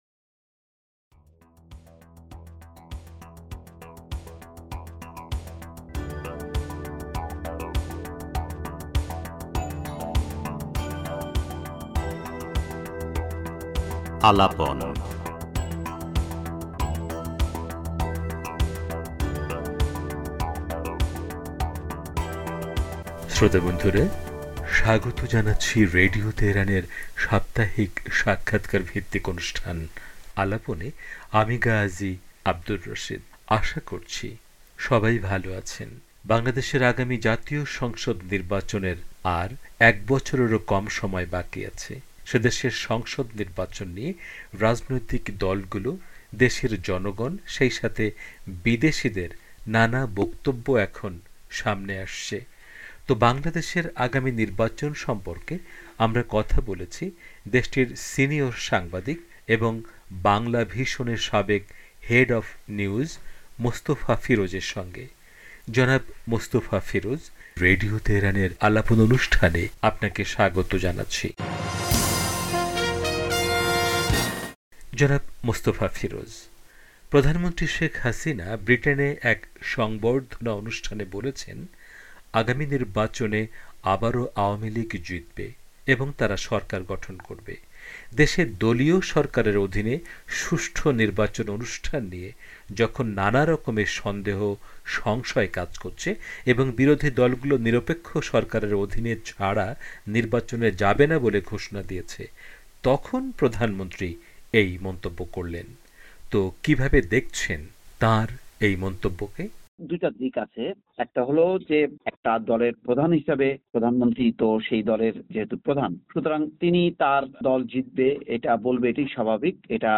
পুরো সাক্ষাৎকারটি তুলে ধরা হলো।